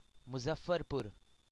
pronunciation) is a city located in Muzaffarpur district on the banks of Burhi Gandak river in the Tirhut division of the Indian state of Bihar.[5][1] It serves as the headquarters of the Tirhut division, the Muzaffarpur district.
Muzaffarpur.ogg.mp3